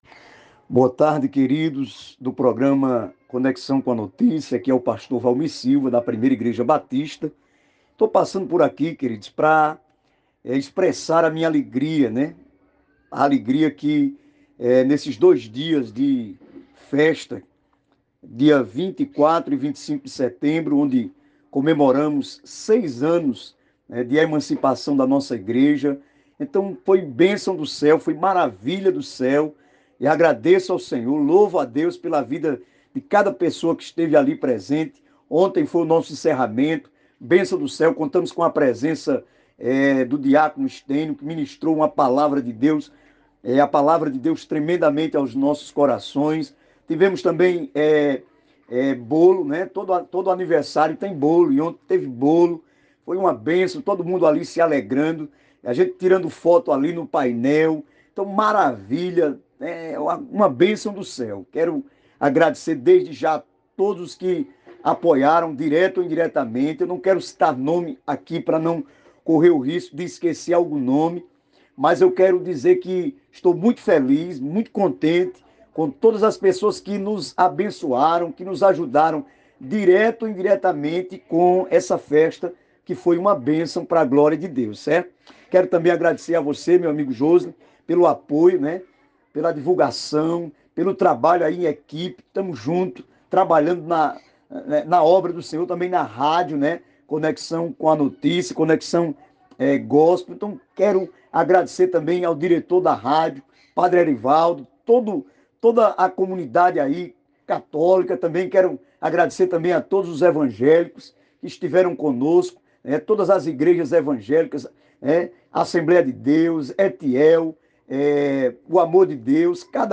Rádio Conexão (104,9 FM)